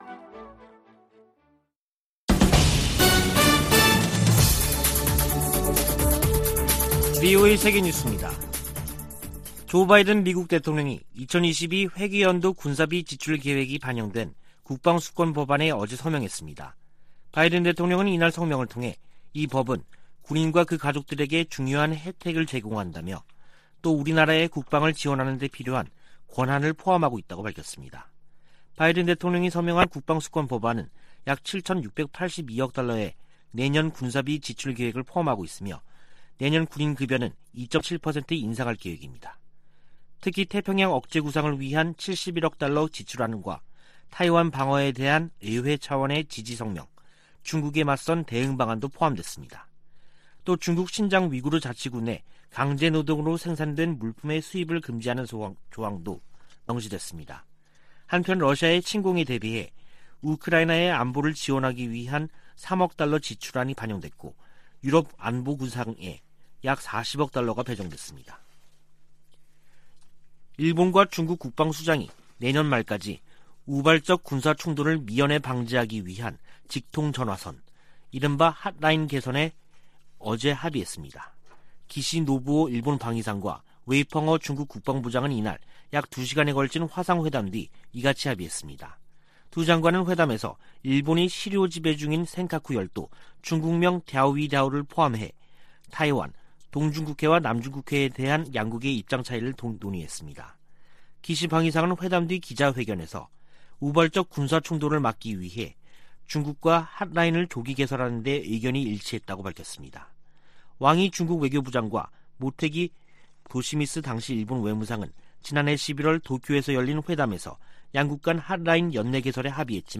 VOA 한국어 간판 뉴스 프로그램 '뉴스 투데이', 2021년 12월 28일 3부 방송입니다. 북한이 27일 김정은 국무위원장 주재로 올 들어 네번째 노동당 전원회의를 개최했습니다. 조 바이든 미국 행정부는 출범 첫 해 외교를 강조하며 북한에 여러 차례 손을 내밀었지만 성과를 거두진 못했습니다.